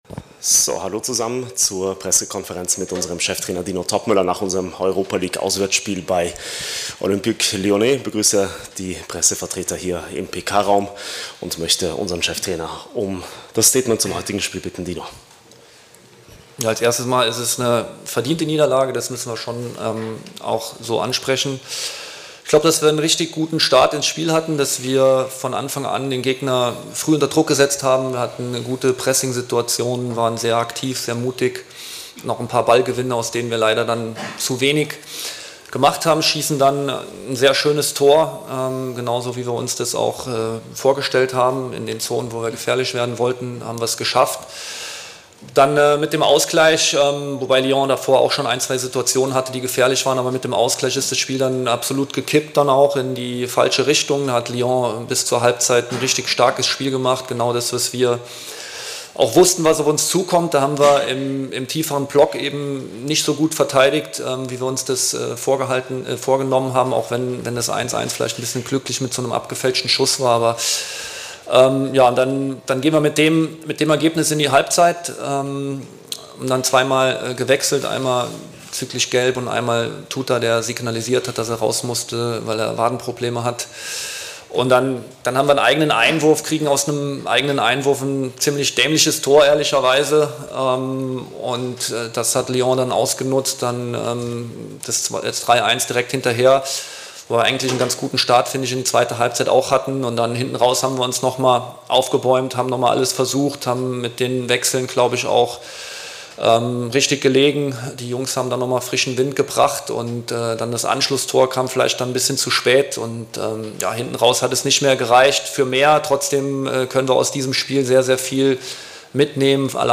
Die Pressekonferenz mit unserem Cheftrainer Dino Toppmöller nach dem Europa-League-Auswärtsspiel gegen Lyon.